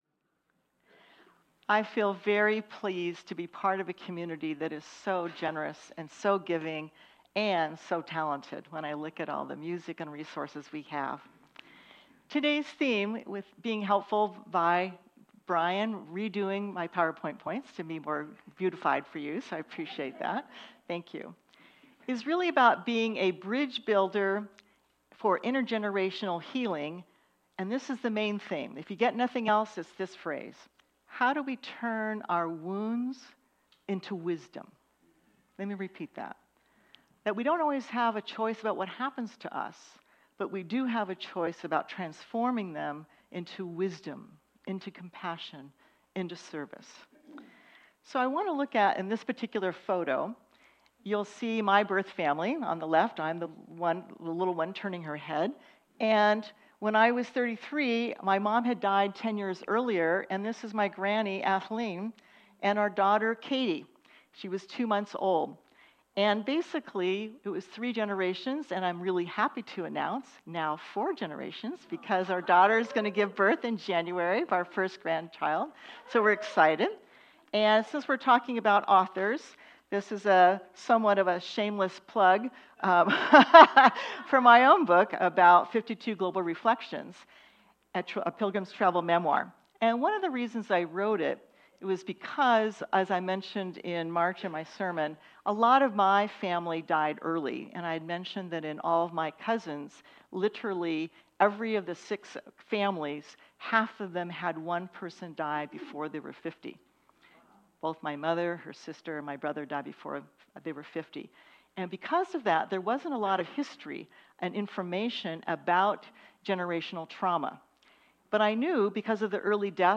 The audio recording (below the video clip) is an abbreviation of the service. It includes the Message, Meditation, and Featured Song.